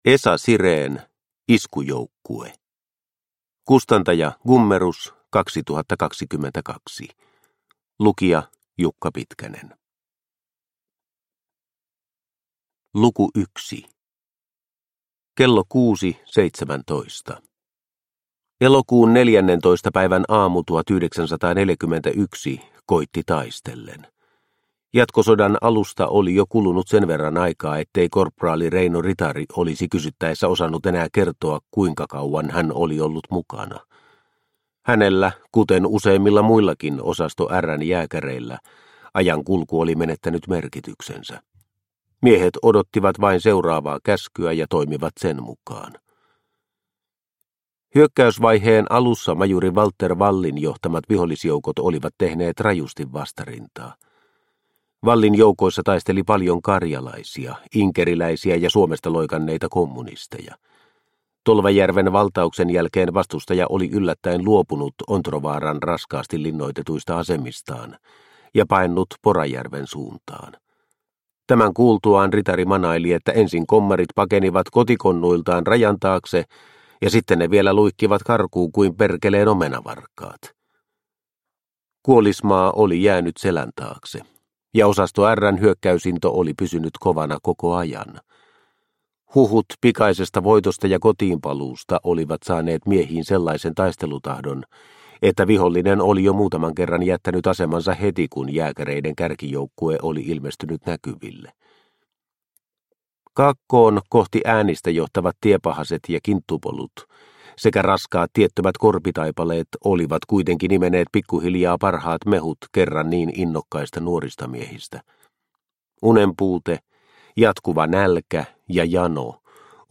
Iskujoukkue – Ljudbok – Laddas ner